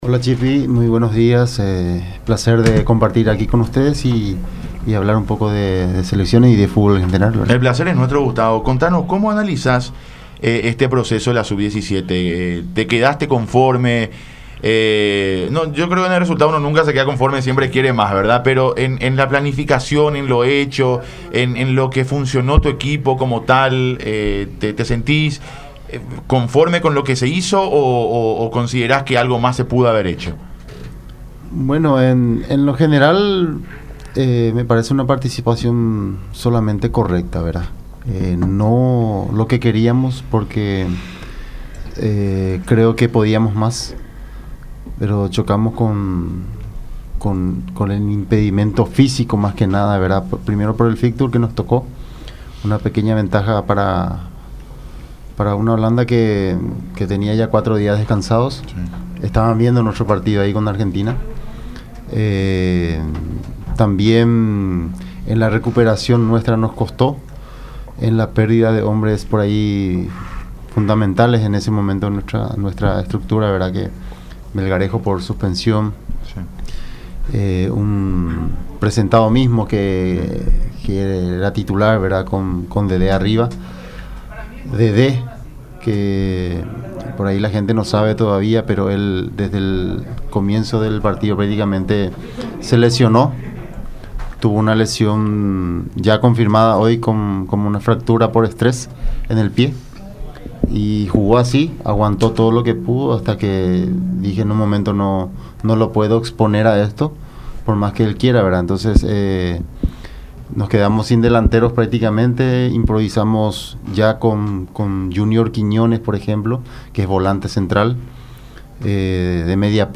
Gustavo Morínigo, entrenador de la Selección Nacional Sub 17, que culminó su participación en el Mundial de Brasil, visitó los estudios de Radio La Unión, para charlar sobre diferentes aspectos del fútbol en referencia específica a la formación de futbolistas en nuestro país.